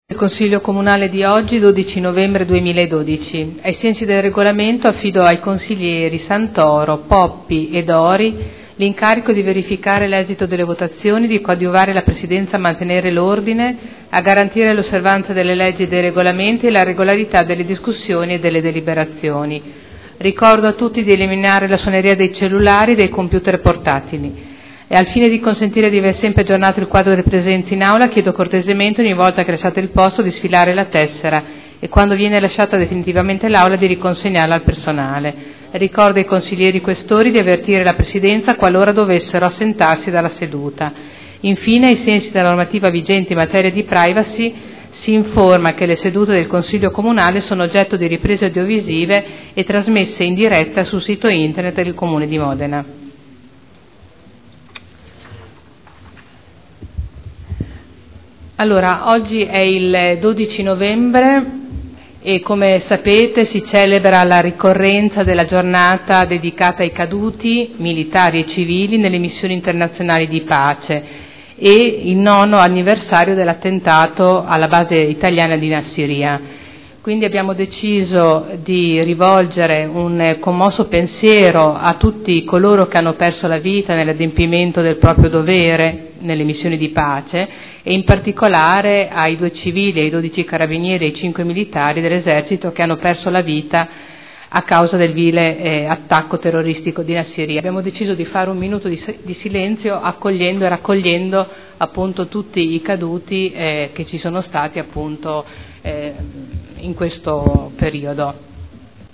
Il Presidente Caterina Liotti apre i lavori del Consiglio.